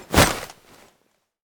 axe_swing.ogg